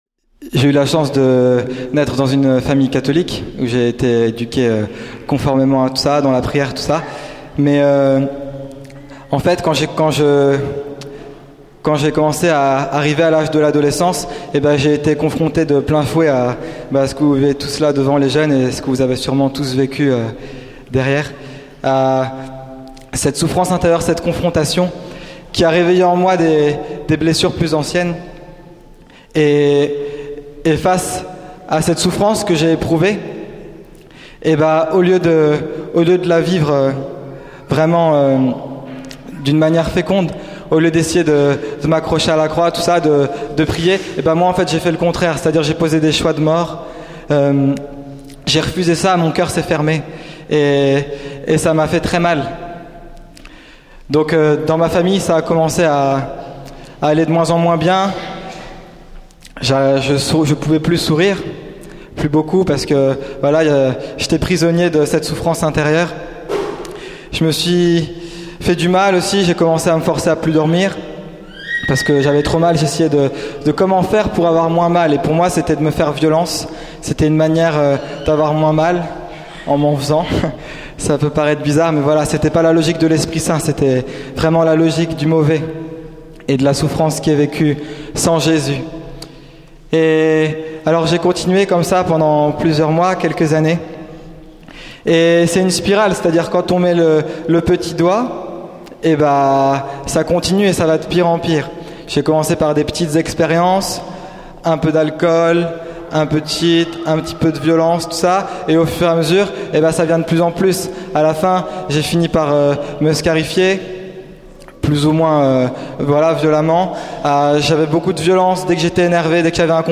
Lisieux 08 Veill�e charismatique
Enregistr� � la Basilique de Lisieux le 5/08/2008